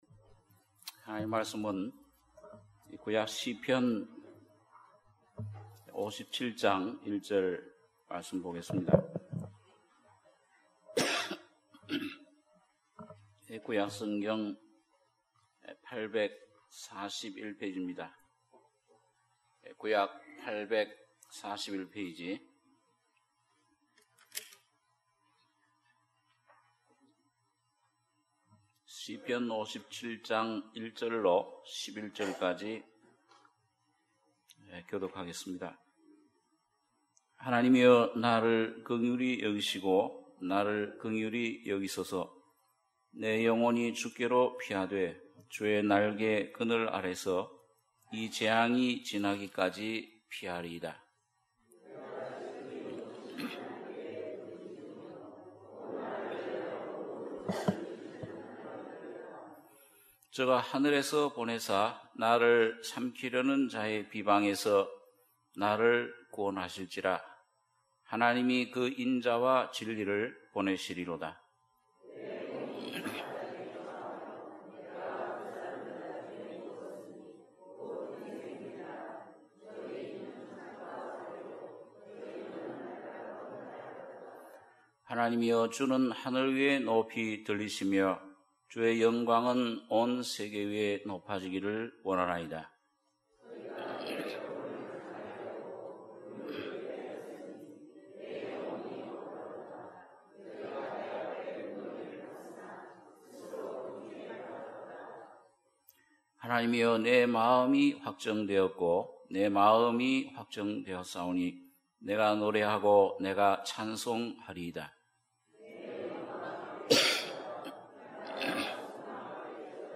주일예배 - 시편 57장 1절~11절